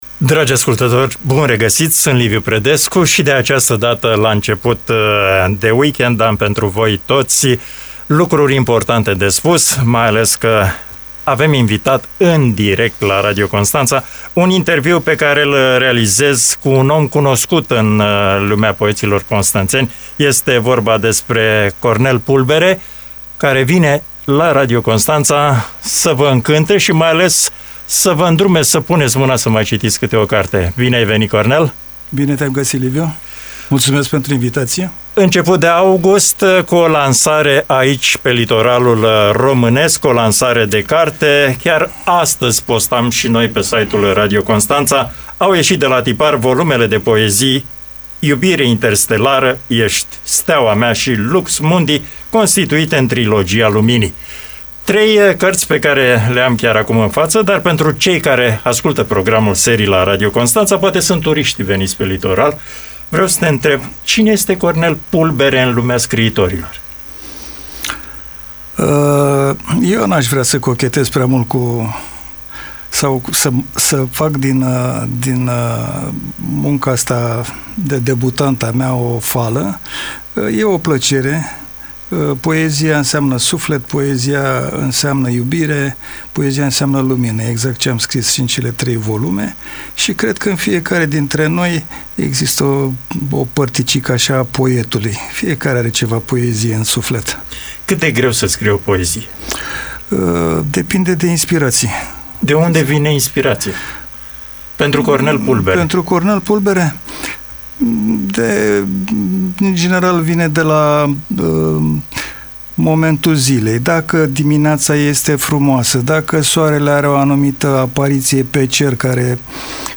Interviu exclusiv.